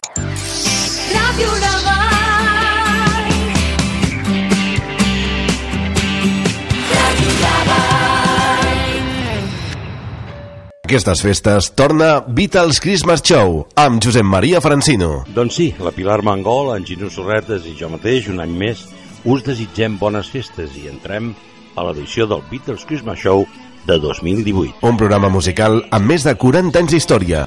Indicatiu de l'emissora i promoció del programa.
FM